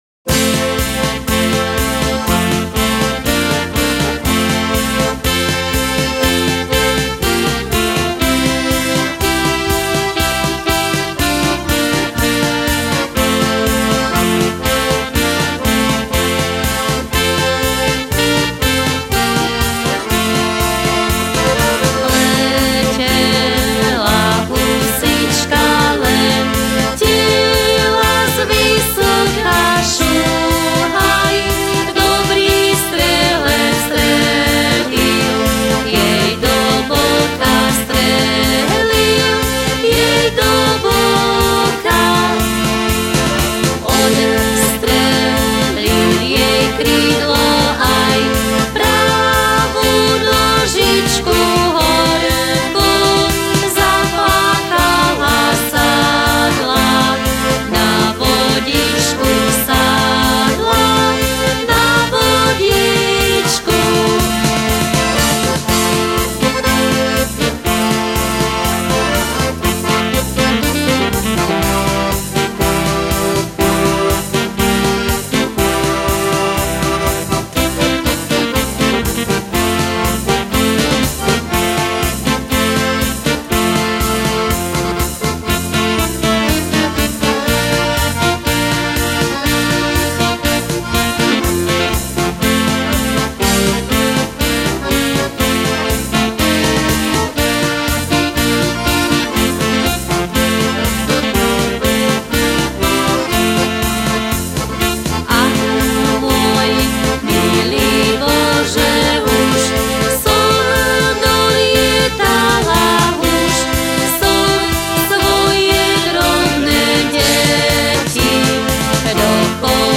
zmes poliek 8.